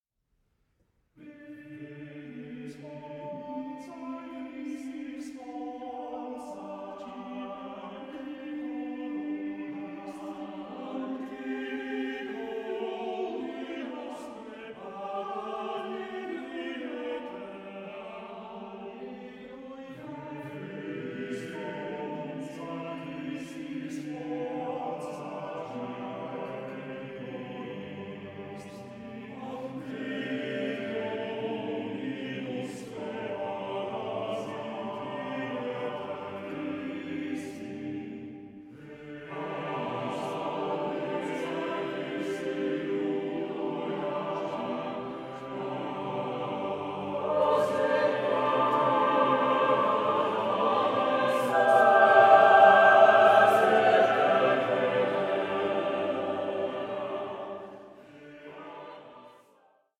SACRED CHORAL MASTERWORKS